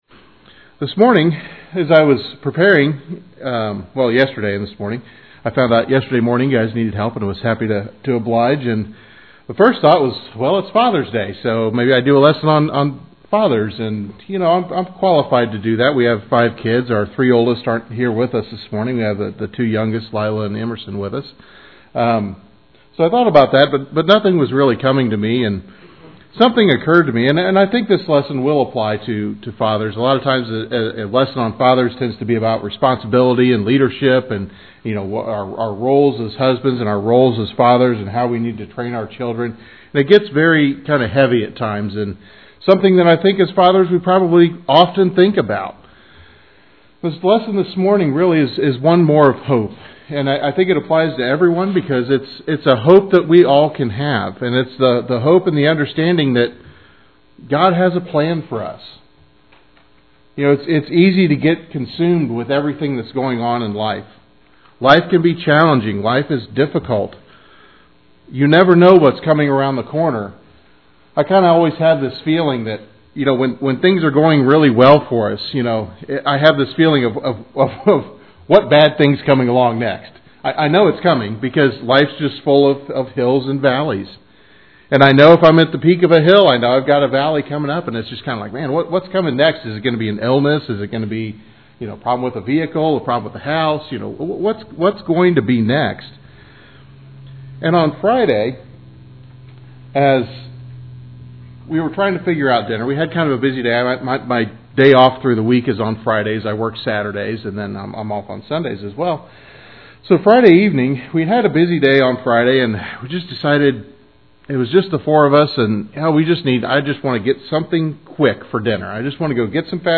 While there are no slides for this lesson, you can listen to his sermon by clicking on the link below.